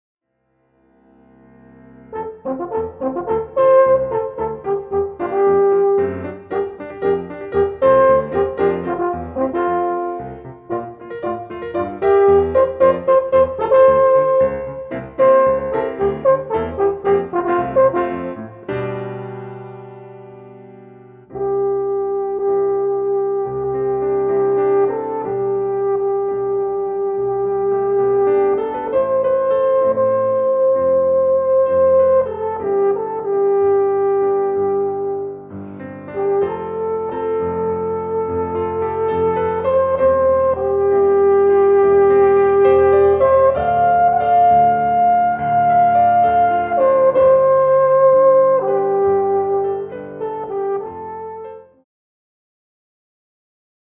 Kompositionen Alphorn (Solo bis Trio) mit Klavier